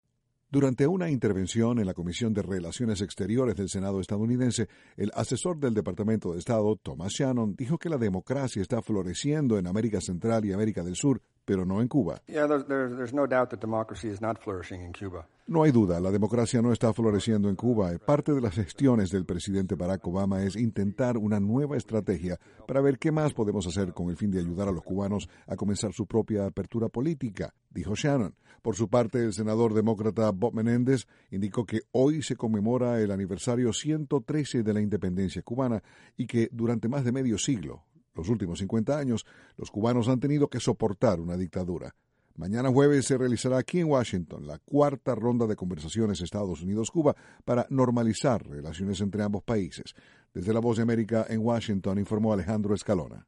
El asesor del Departamento de Estado Thomas Shannon dijo que el presidente Barack Obama busca maneras de ayudar a los cubanos. Desde la Voz de América, Washington